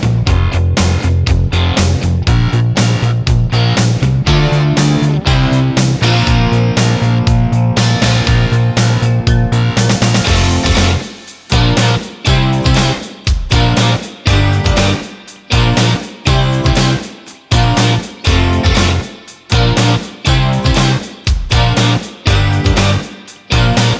Minus Main Guitars Pop (1980s) 4:12 Buy £1.50